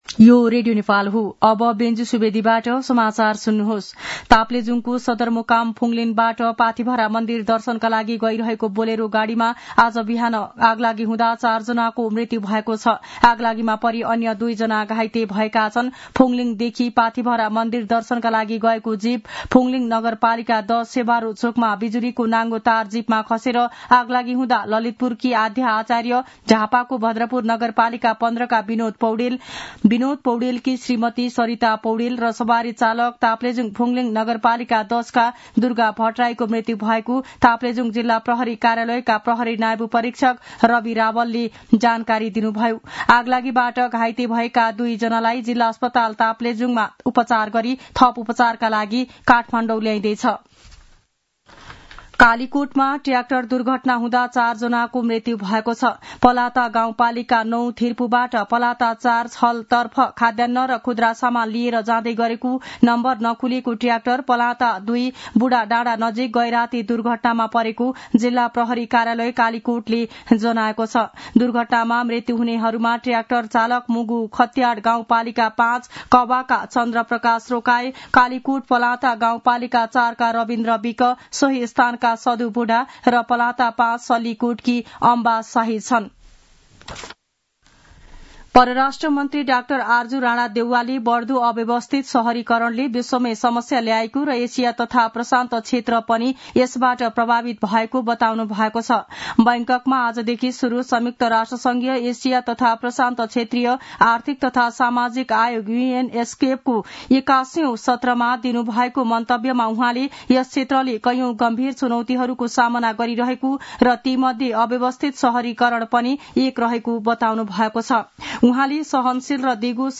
दिउँसो १ बजेको नेपाली समाचार : ८ वैशाख , २०८२